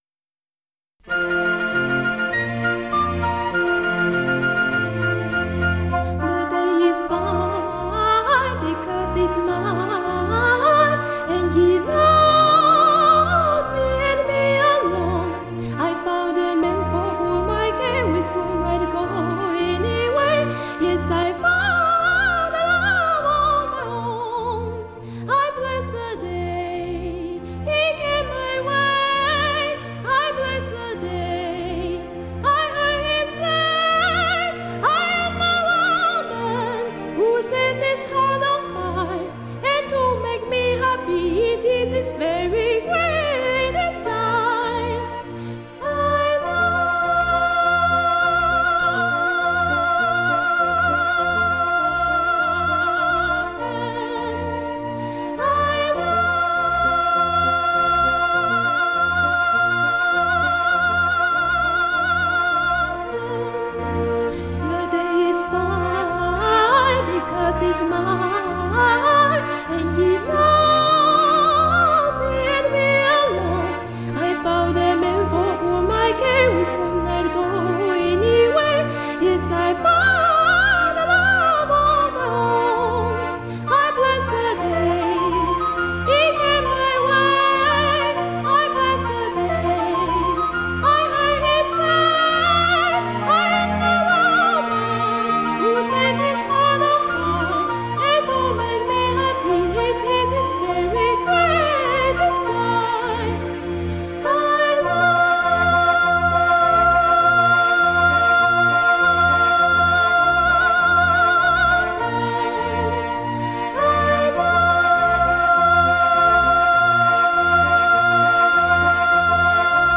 Distress Call     - a Light Opera